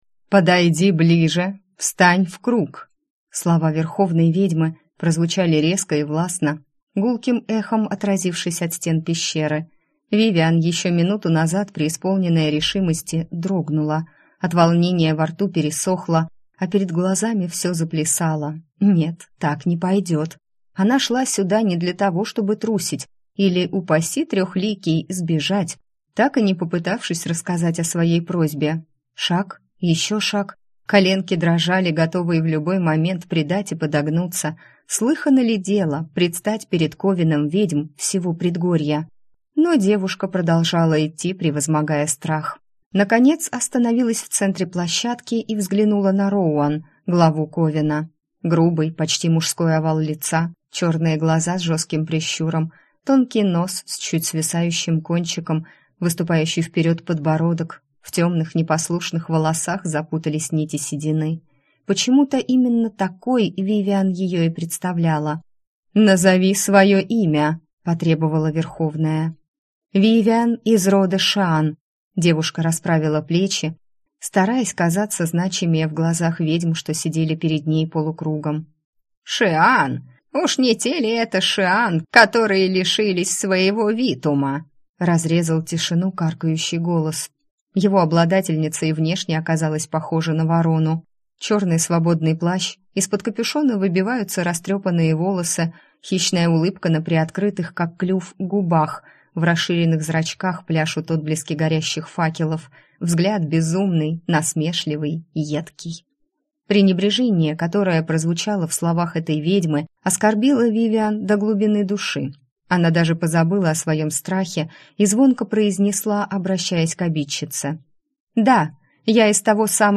Аудиокнига Голос ведьмы | Библиотека аудиокниг